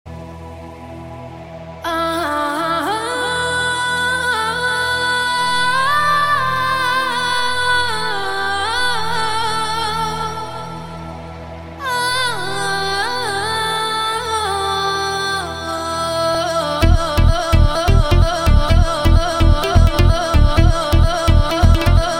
Reggae Ringtones